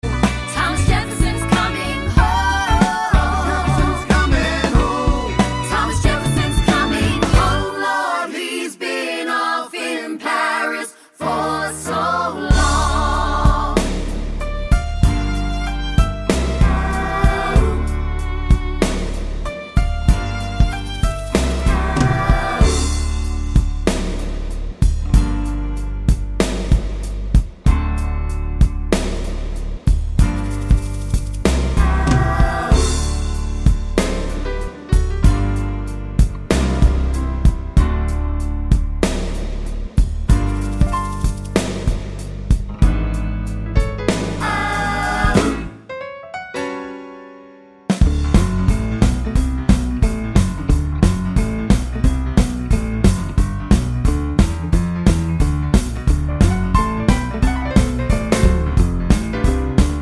no Backing Vocals Musicals 3:57 Buy £1.50